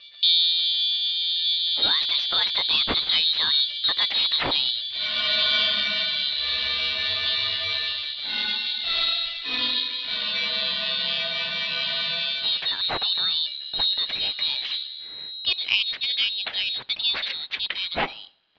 Originalsignal mit 4 kHz multipliziert, Tiefpass-gefiltert, wieder mit 4 kHz multipliziert und wieder Tiefpass-gefiltert.
Im Anhang 3 Files, alle mit 4 kHz Trägerfrequenz und Tiefpass: Das invertierte Test-File mit 48 kHz Tiefpass: SpecInvert_Tagesschau_InvLPF48dB.mp3 Versucht mal, 'rauszukriegen, was das Original ist. In der Mitte der Sequenz habt ihr eine Chance.
SpecInvert_Tagesschau_InvLPF48dB.mp3